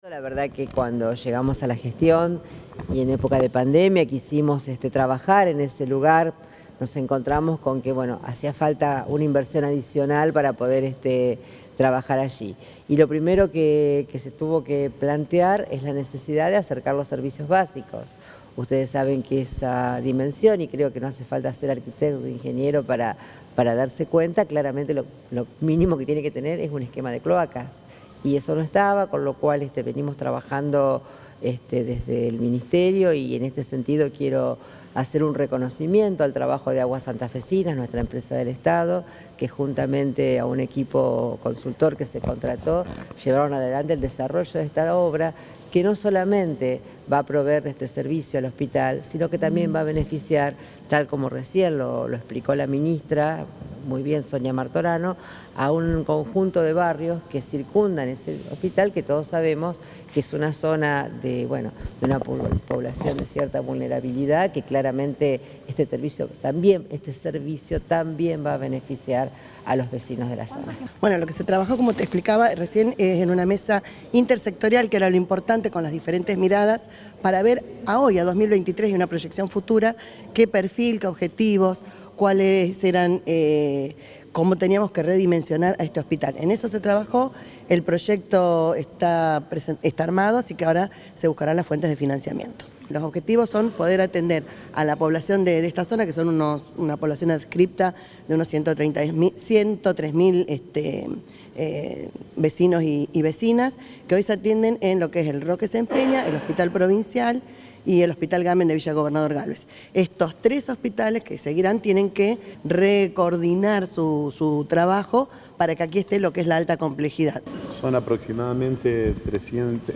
Durante el acto, se licitó la obra de la red cloacal que beneficiará a 38 mil vecinos de la zona.